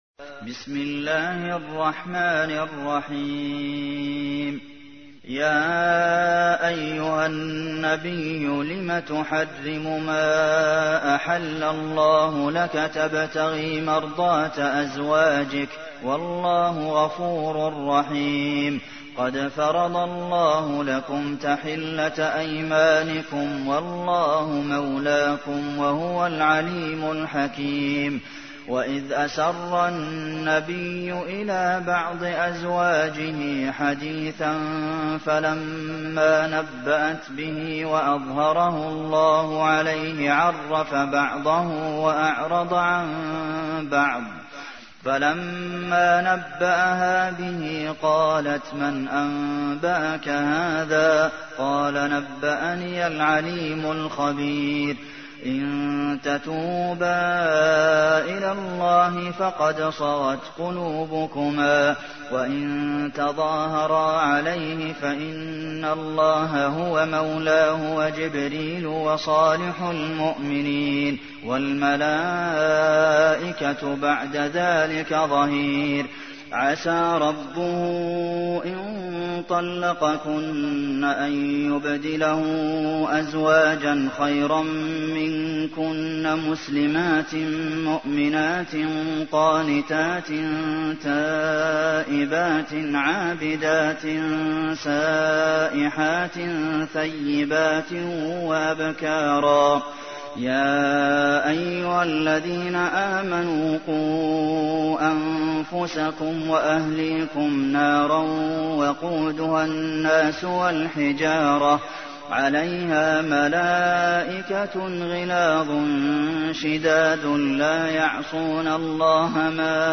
تحميل : 66. سورة التحريم / القارئ عبد المحسن قاسم / القرآن الكريم / موقع يا حسين